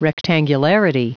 Prononciation du mot rectangularity en anglais (fichier audio)
Prononciation du mot : rectangularity